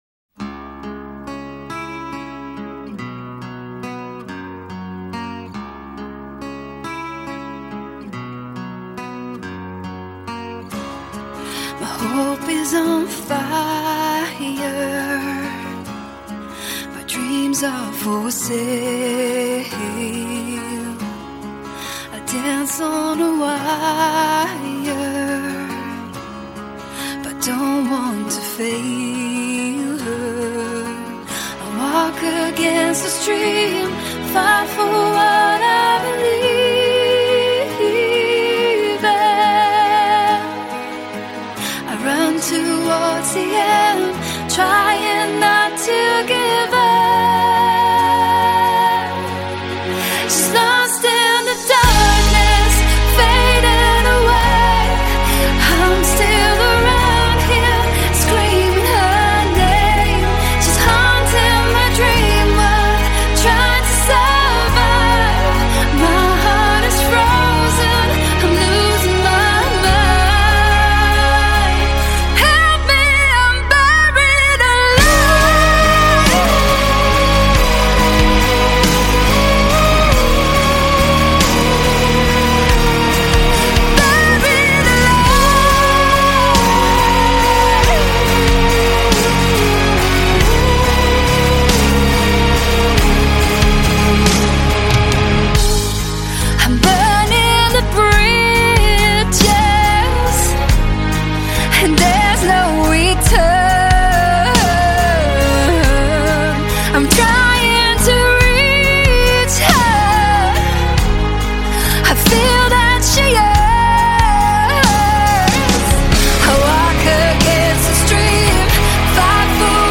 Жанр: Hard Rock